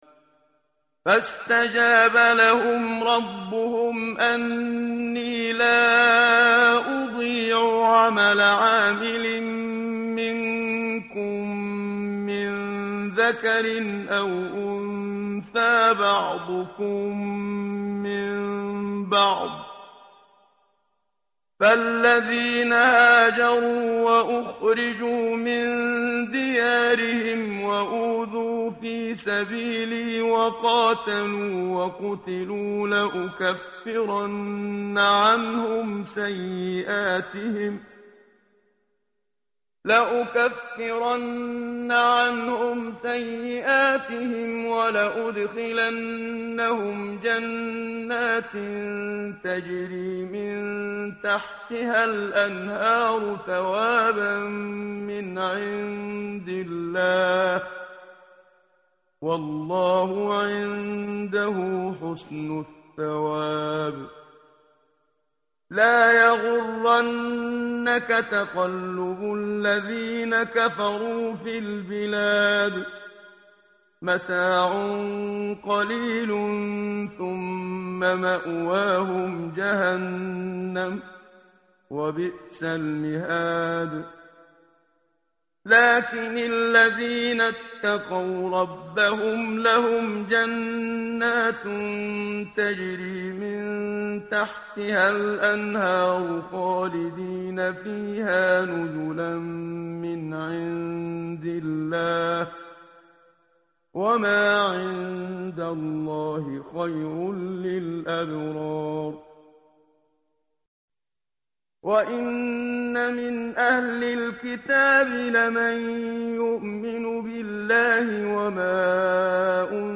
متن قرآن همراه باتلاوت قرآن و ترجمه
tartil_menshavi_page_076.mp3